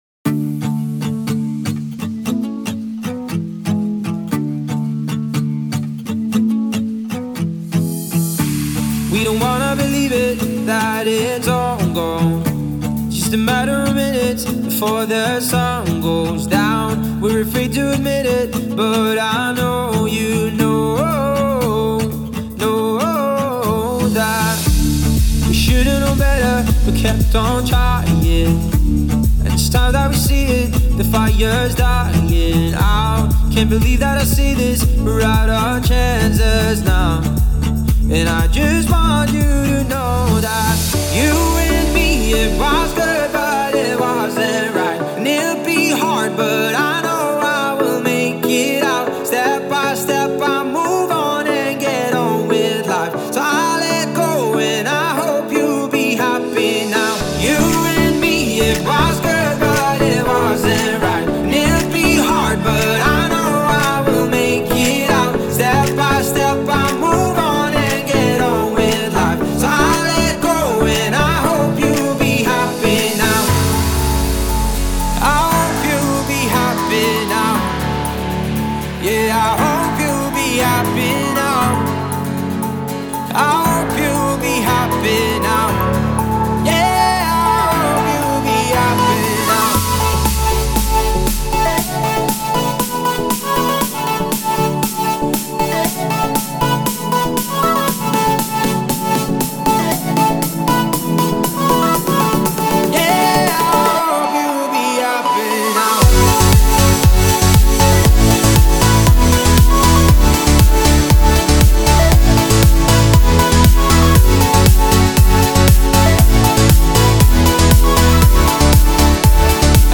Genre: Electronic Dance